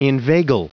Prononciation du mot inveigle en anglais (fichier audio)
Prononciation du mot : inveigle